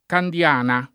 [ kand L# na ]